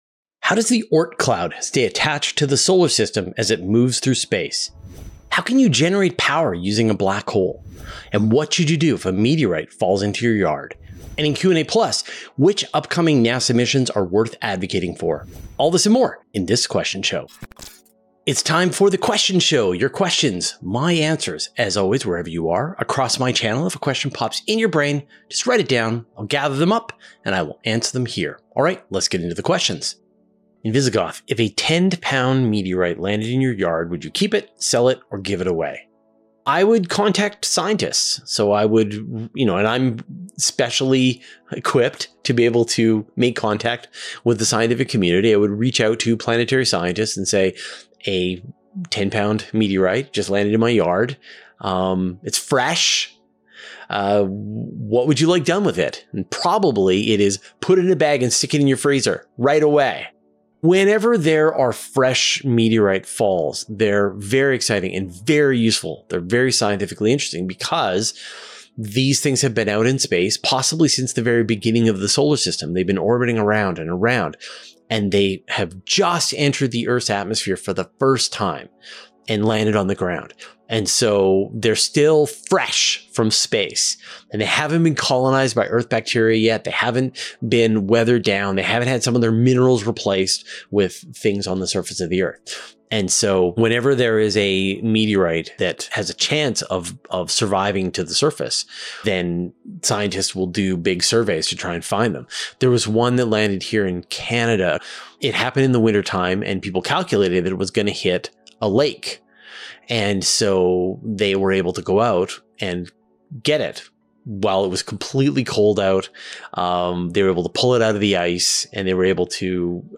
Space news, interviews, Q&As, and exclusive content from Universe Today.